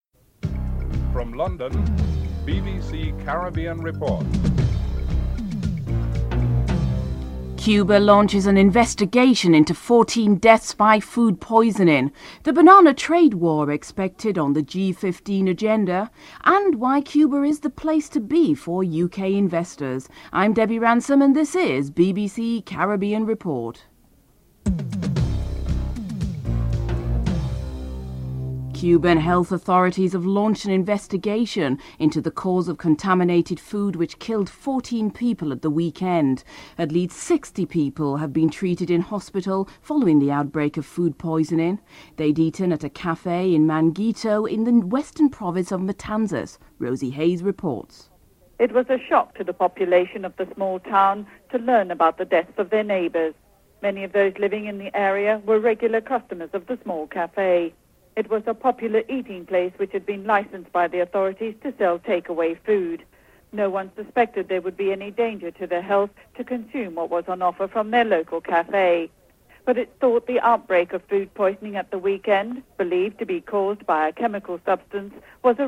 Prime Minister of Trinidad and Tobago Basdeo Panday in his welcoming speech said that the new center will promote the country’s cultural and ethnic diversity.